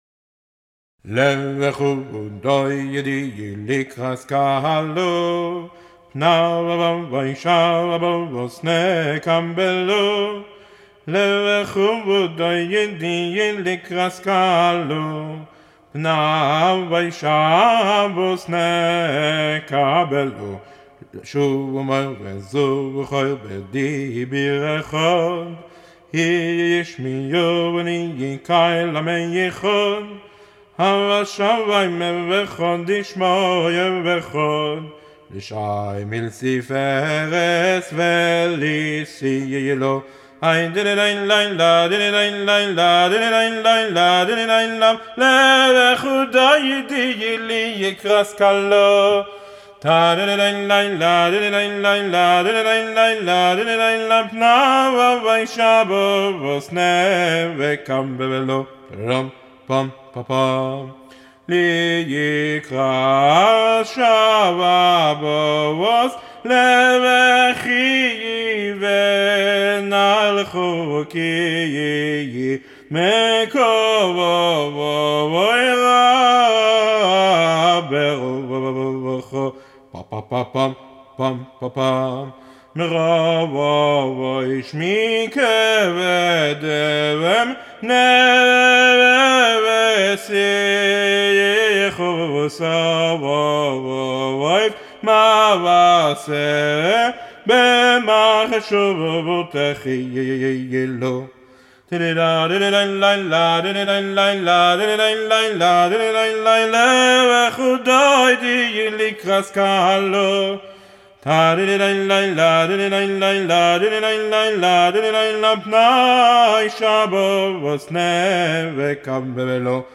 כלום רק ריוורב כי זה הקונספט כשמקליטים לא באולפן…